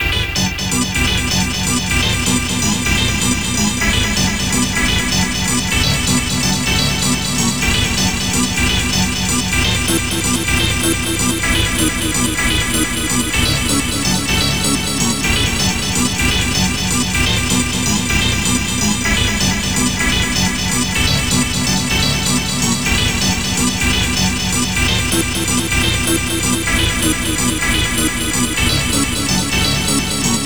Synth
rave sequence spirit 2 - Cm - 126.wav
rave_sequence_black_train_2_NbO.wav